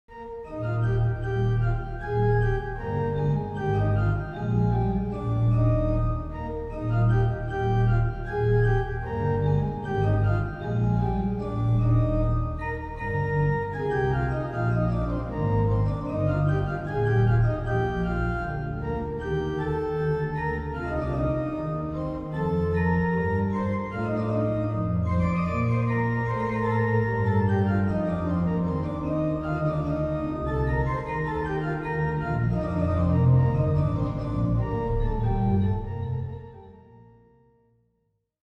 Description:   This is a collection of organ transcription.